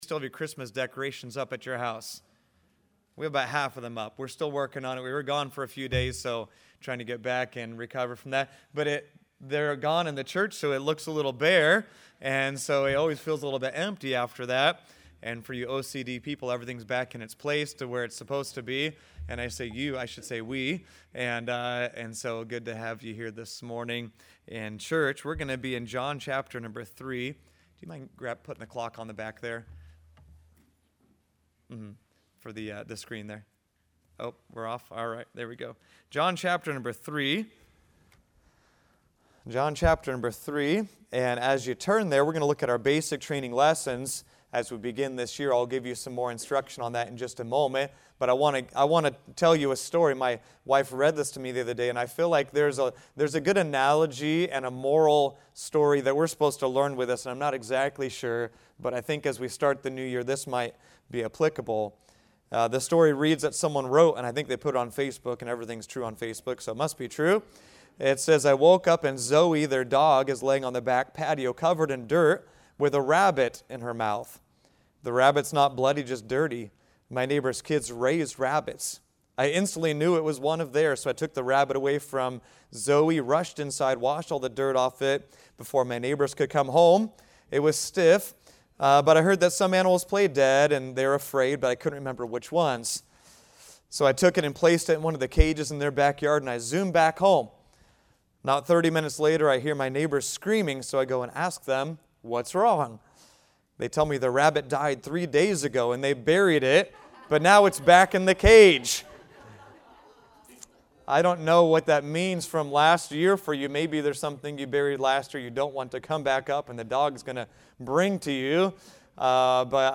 Salvation | Sunday School